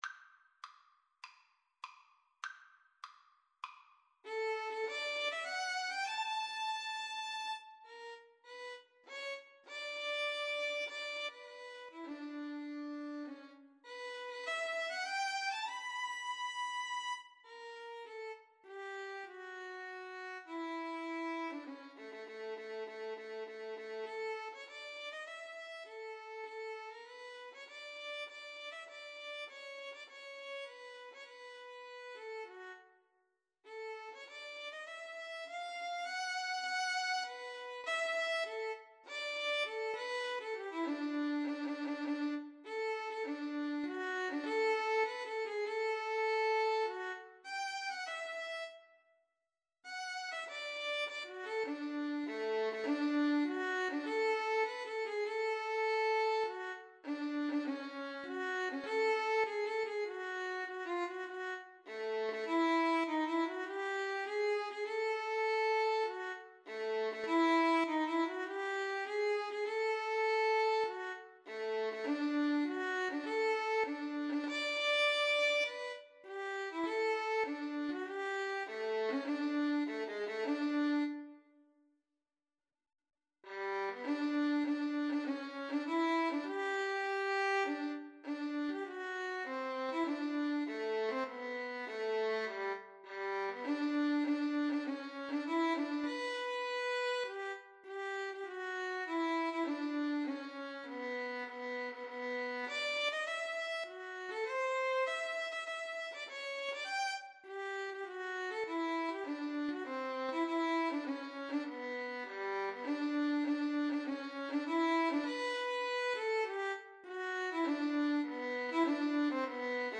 4/4 (View more 4/4 Music)
Marcial
Traditional (View more Traditional Violin-Cello Duet Music)